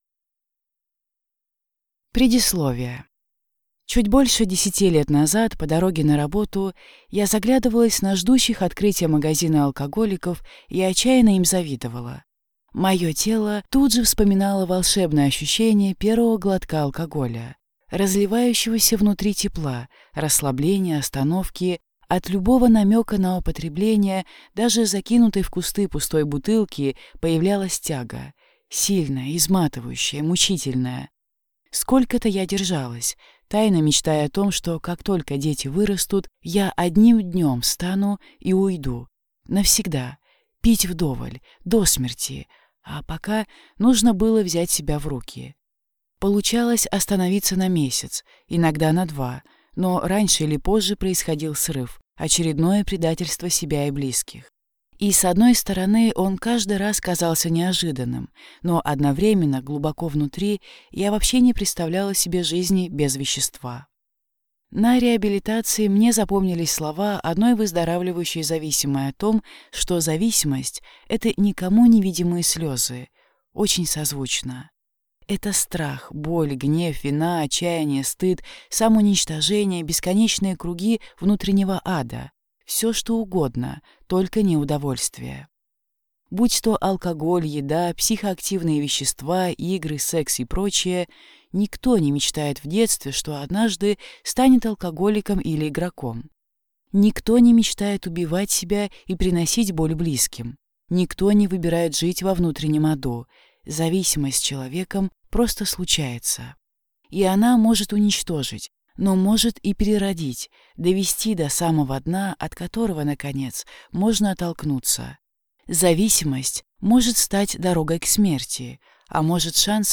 Аудиокнига Зависимость. Тревожные признаки алкоголизма, причины, помощь в преодолении | Библиотека аудиокниг